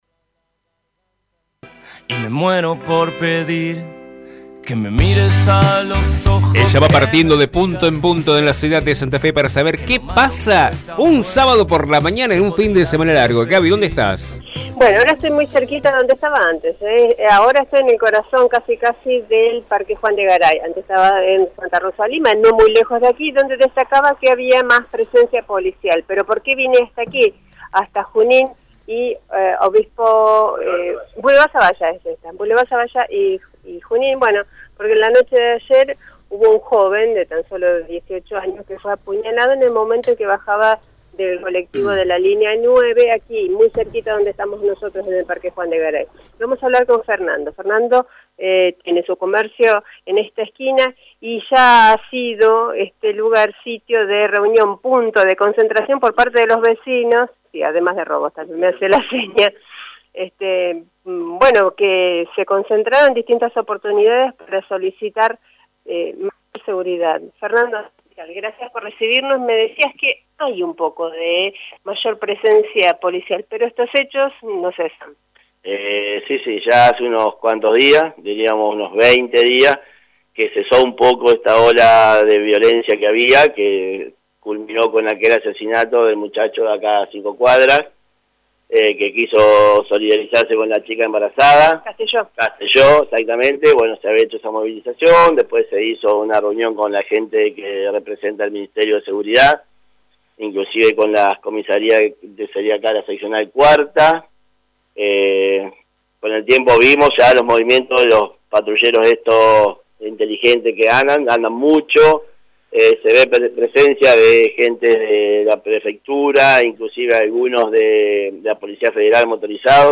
Hoy les tocó a las familias que viven en los alrededores del Parque Juan de Garay. Allí estuvo el móvil de Radio EME